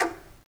Tumba-Tap1_v1_rr2_Sum.wav